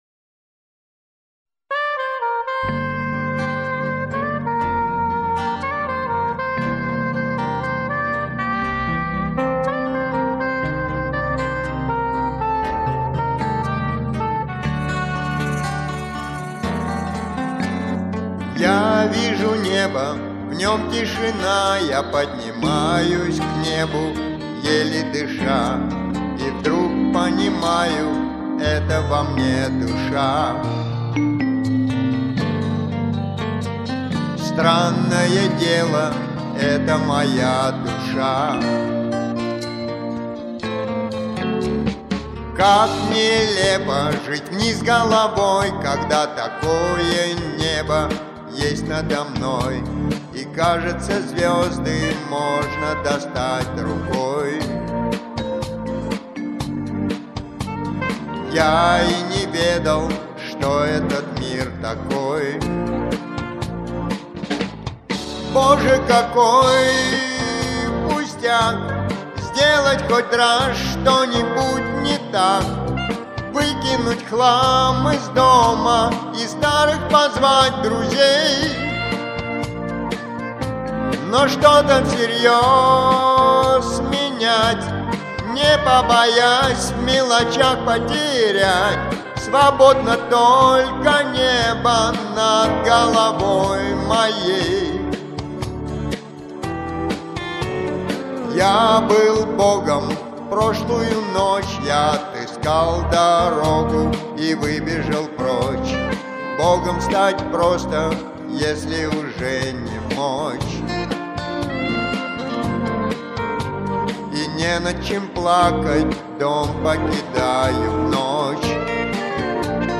вы тут, оба-два, звучите гораздо брутальнее)....